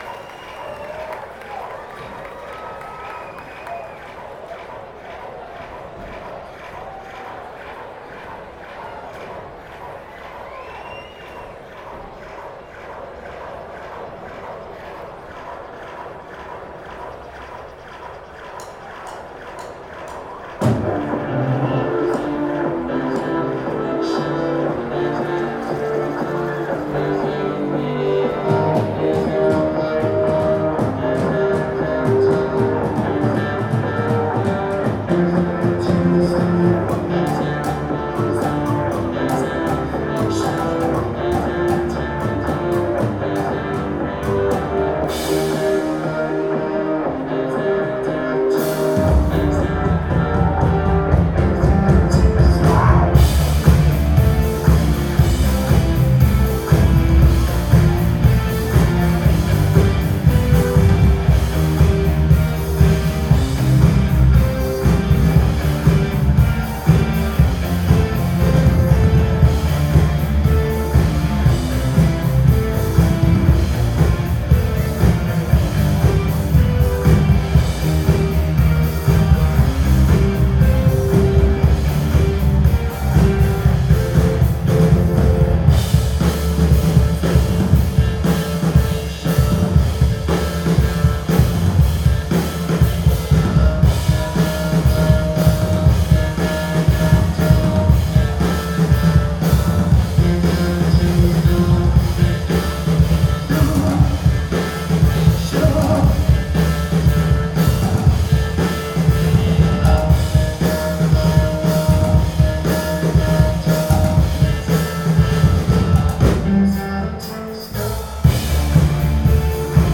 Brooklyn Bowl 07-24-10